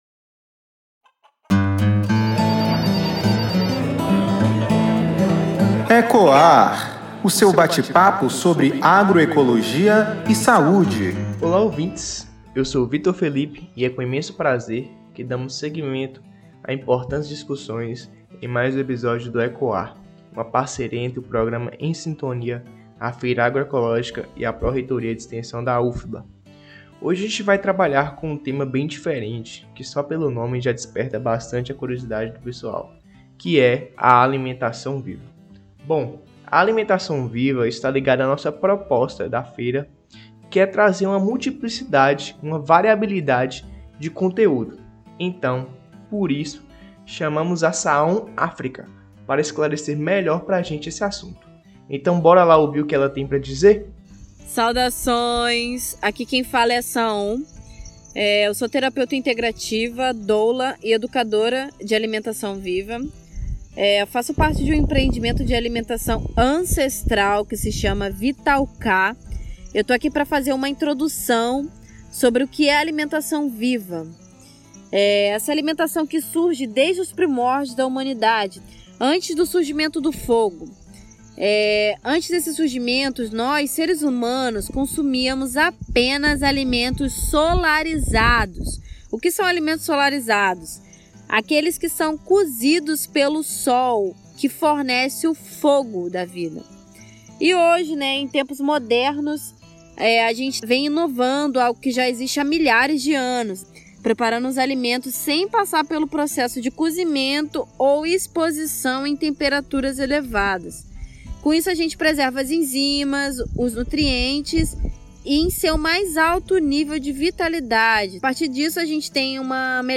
Entrevistador
Entrevistada